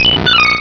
sovereignx/sound/direct_sound_samples/cries/marill.aif at 2f4dc1996ca5afdc9a8581b47a81b8aed510c3a8